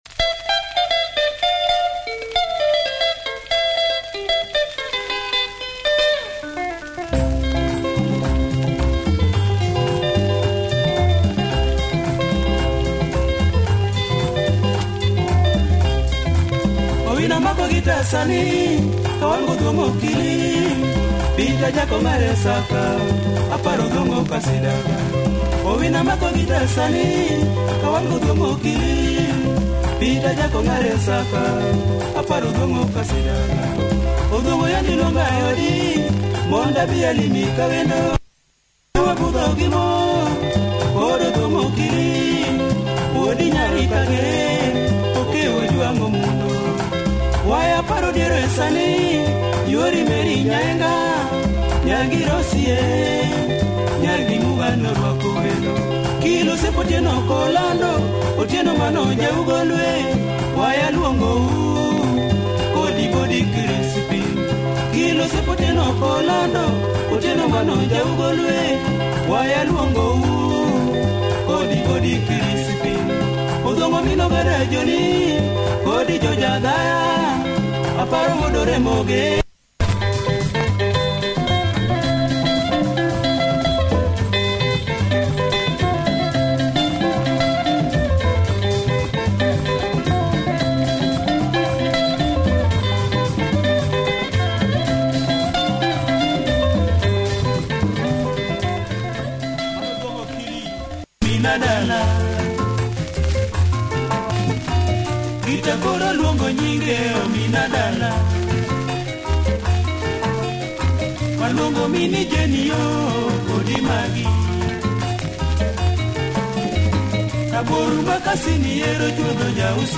Early LUO benga, disc has some wear but plays okay. https